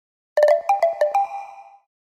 Звук подтверждения транзакции в Google Pay